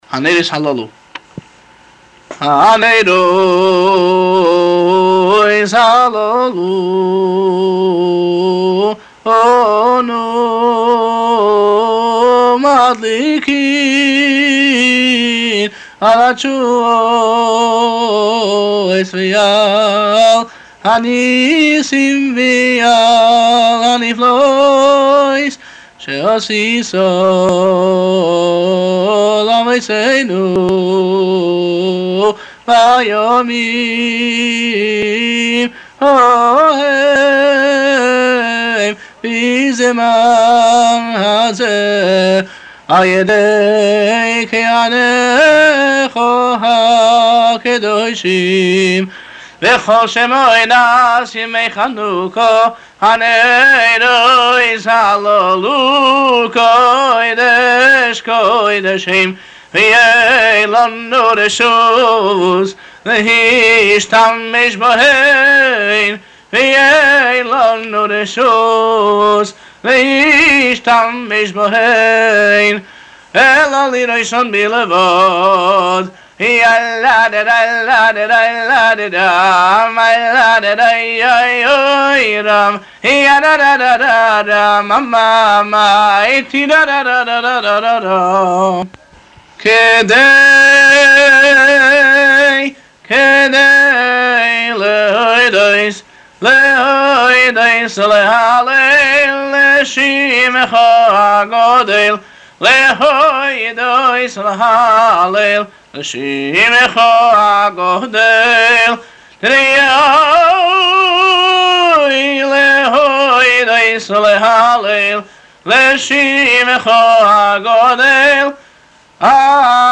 ה ניגון הנרות הללו שמופיע בפרוייקט מסורת הניגונים , הינו ניגון חב"די עתיק שהיה מקובל בפי חסידי חב"ד, לצד הניגון המוכר והנפוץ יותר. מילותיו הן תפילת השבח וההודיה הנאמרת לאחר הדלקת נרות חנוכה .